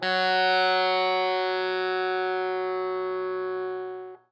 SPOOKY    AI.wav